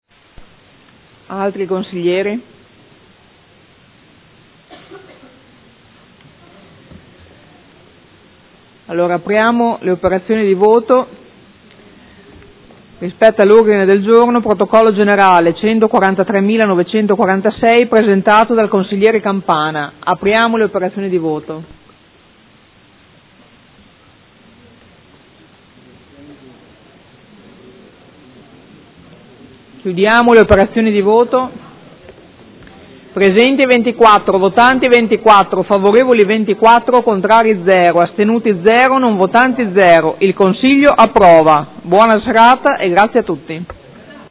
Presidentessa